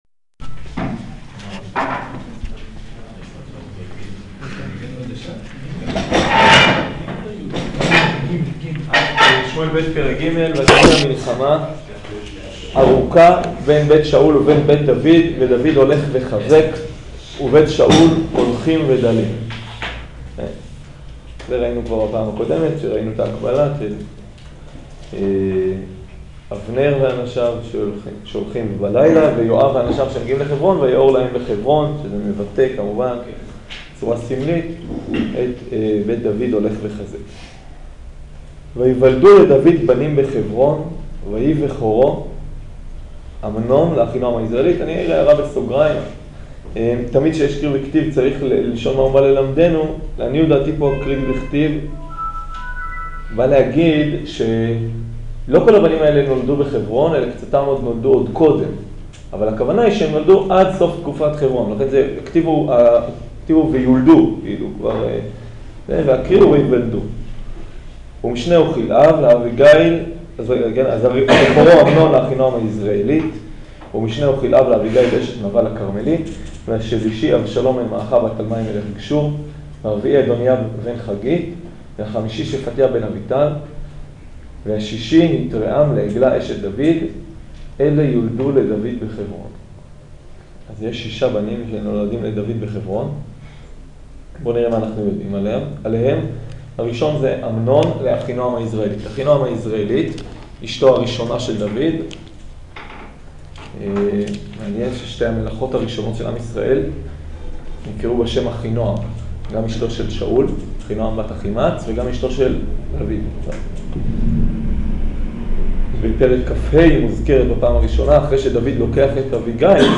שיעור שמואל ב' פרק ג'